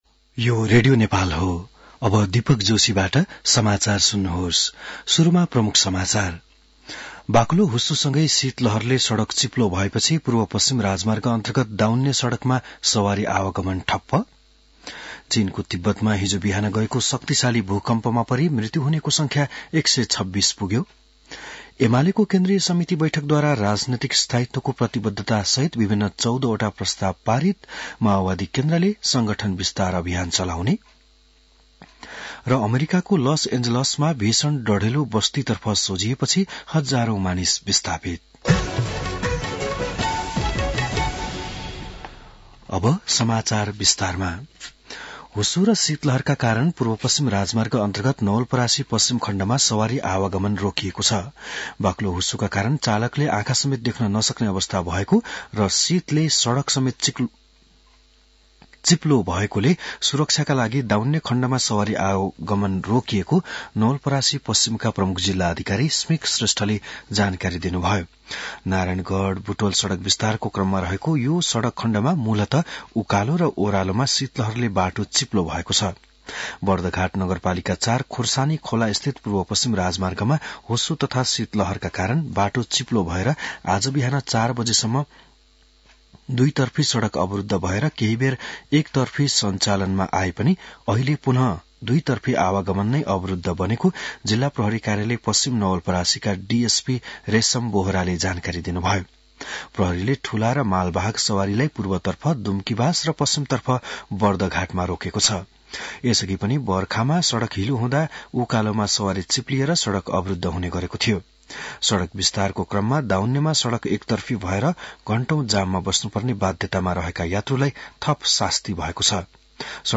An online outlet of Nepal's national radio broadcaster
बिहान ९ बजेको नेपाली समाचार : २५ पुष , २०८१